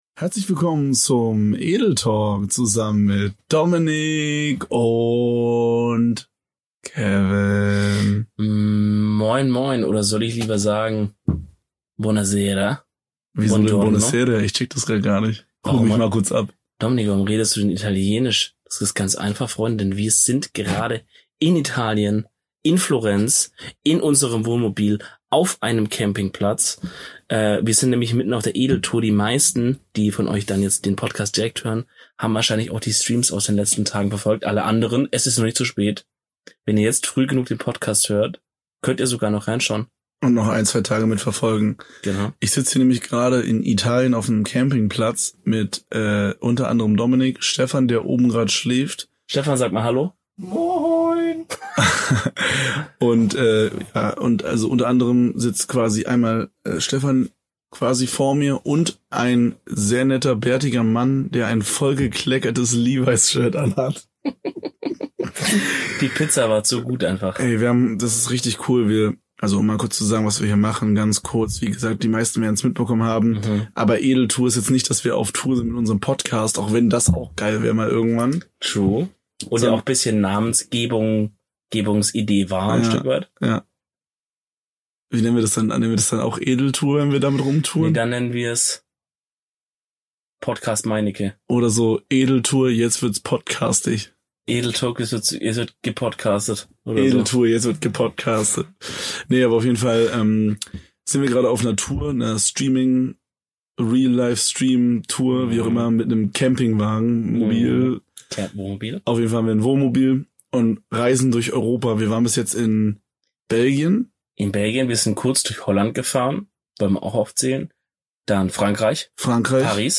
Diese Episode haben die Jungs mitten auf der Edeltour aus dem Wohnmobil aufgenommen und über ihre bisherigen Erlebnisse erzählt. Camping Feeling inklusive!